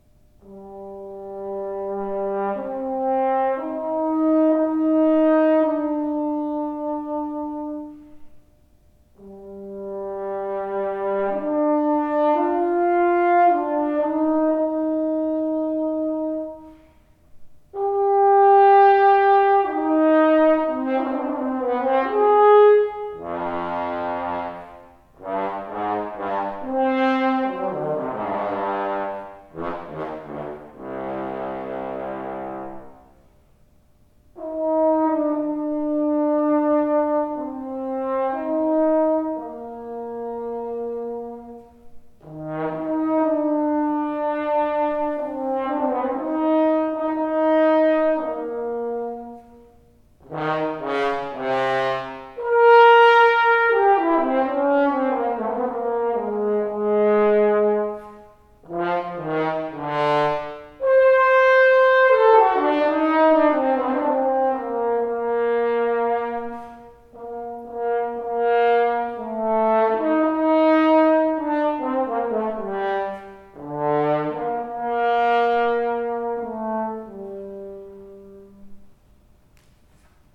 I have been doing some thinking recently on this topic, and decided to record some more audio examples of the two flares I currently own for my Engelbert Schmid double horn: a lacquered, spun flare, and an unlacquered, hand hammered flare with a garland.
First, I recorded these short excerpts in a larger space, with the microphones placed approximately twenty feet away, and second I am asking readers to take a very short poll to determine which bell is generally preferred by listeners.
Please note that while every attempt was made to perform the excerpts in as consistent a manner as possible, there is the occasional “blip” as well as some extraneous noise in the hall (not created by me).
Bell 2 – Darker Sound, better fitting in the low range on soft/medium dynamics but not on the forte attacks in the low range. On the high range sound flat (maybe for the darker sound)
spunbell_excerpt1.mp3